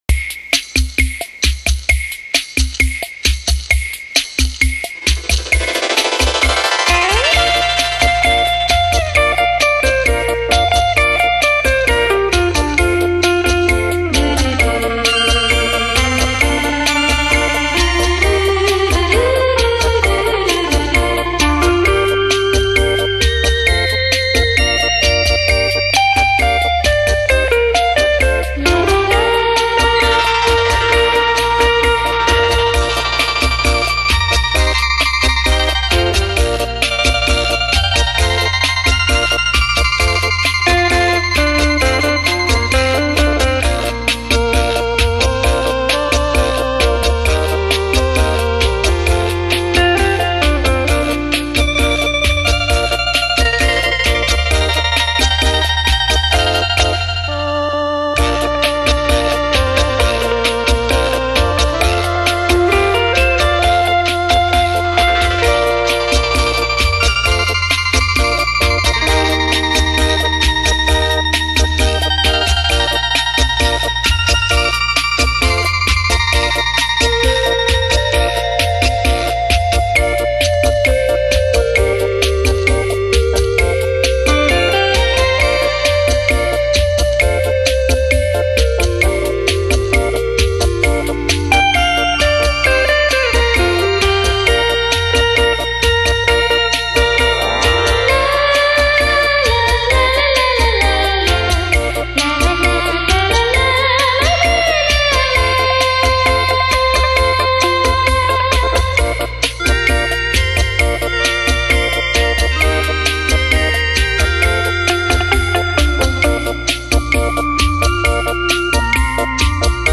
[23/8/2009]『双电子琴音乐欣赏』===附（双排键电子琴）图片 激动社区，陪你一起慢慢变老！